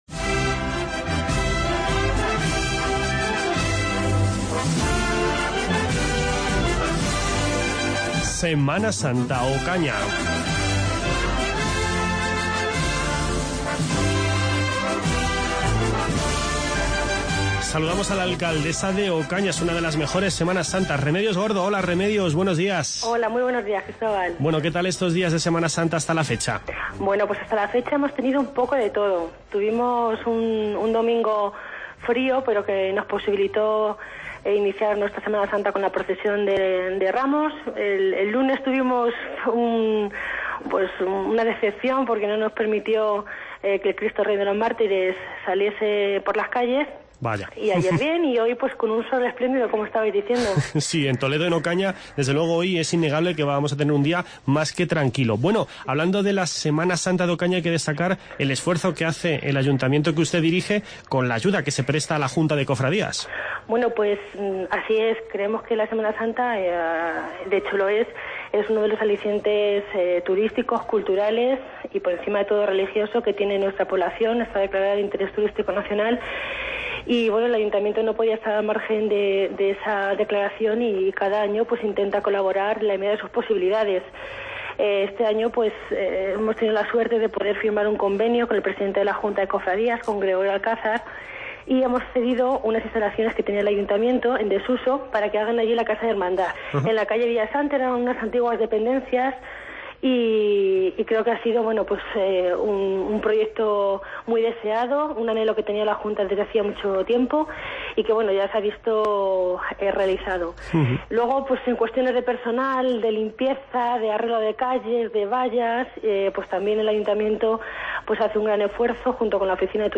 Seguimos nuestro recorrido por las mejores Semanas Santas de Castilla-La Mancha. Hoy, en primer lugar, charlamos con la alcaldesa de Ocaña, Remedios Gordo.
A continuación, conversamos con el alcalde de Hellín, Ramón García Rodríguez, ya que esta tarde comienza la tamborada.